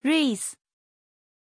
Aussprache von Reese
pronunciation-reese-zh.mp3